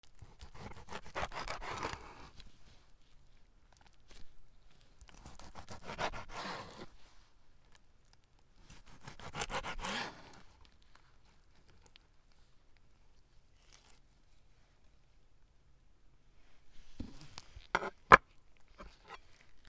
鯖を切る 料理